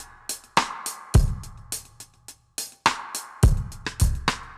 Index of /musicradar/dub-drums-samples/105bpm
Db_DrumsA_Wet_105_01.wav